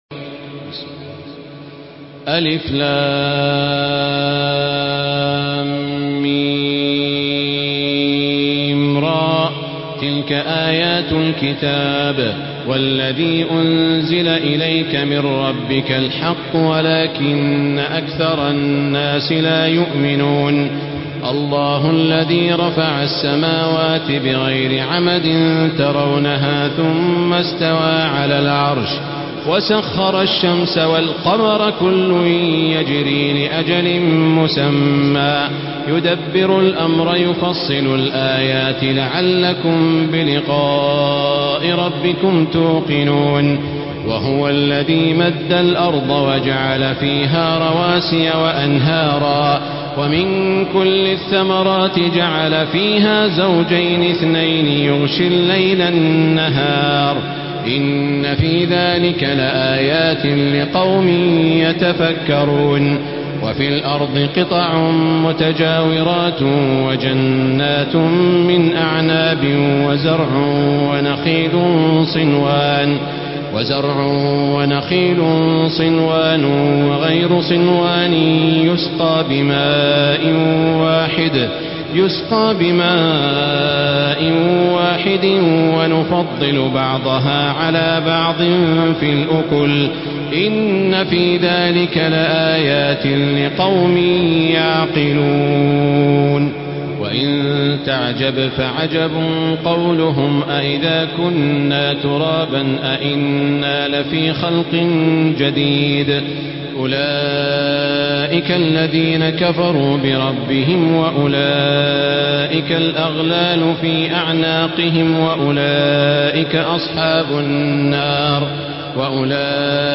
Surah আর-রা‘দ MP3 by Makkah Taraweeh 1435 in Hafs An Asim narration.
Murattal